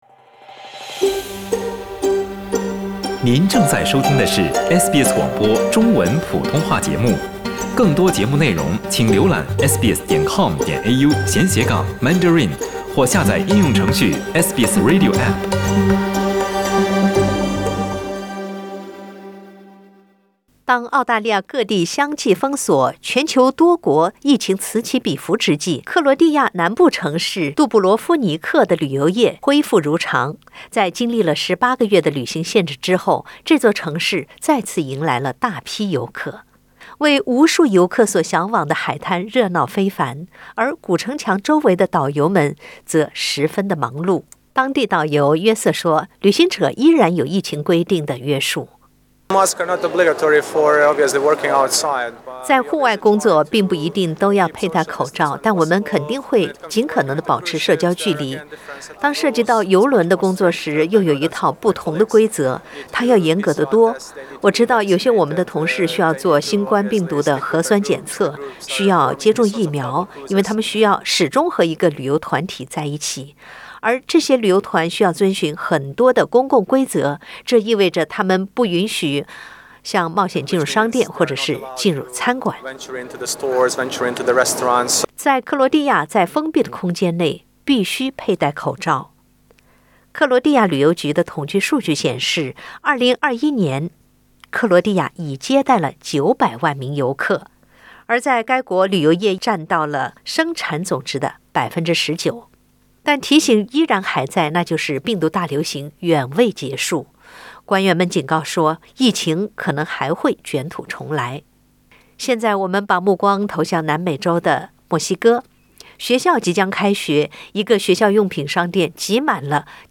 官员们警告，病毒大流行远未结束，疫情可能还会卷土重来。（点击上图收听报道）